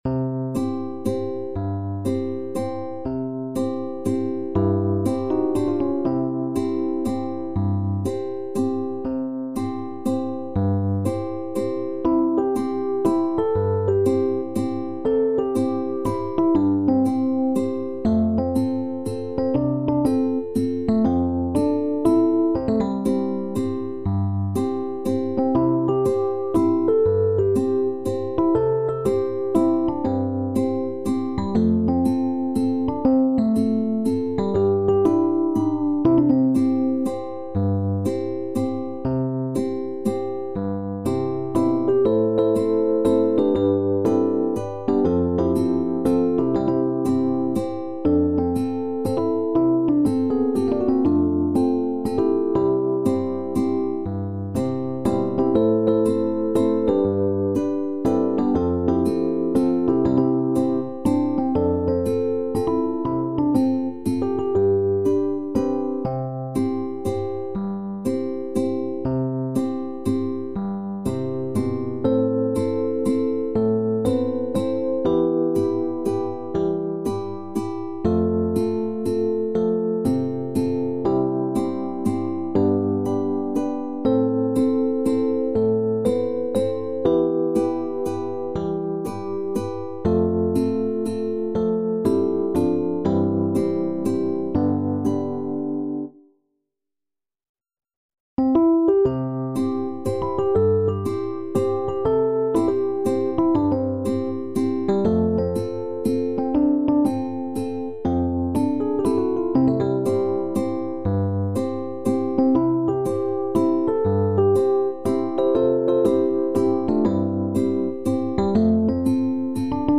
SSATB avec ukelele | SATTB avec ukelele